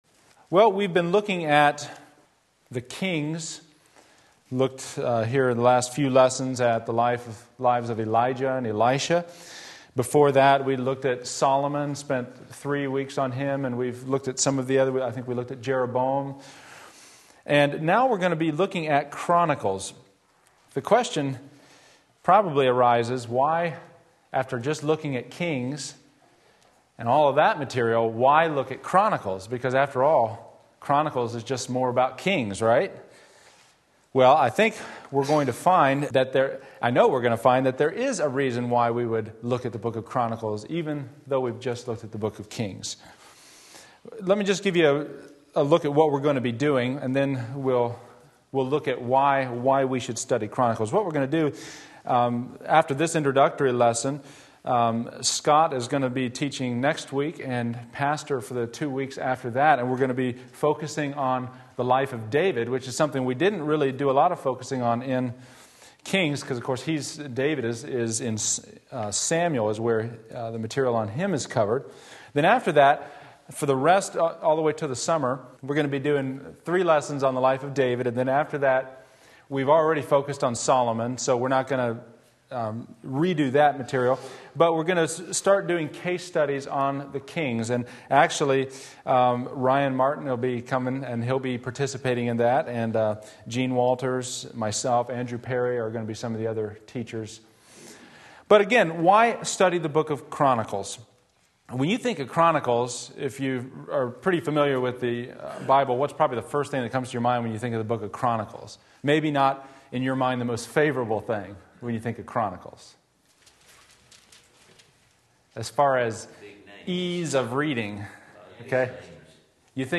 2 Chronicles Sunday School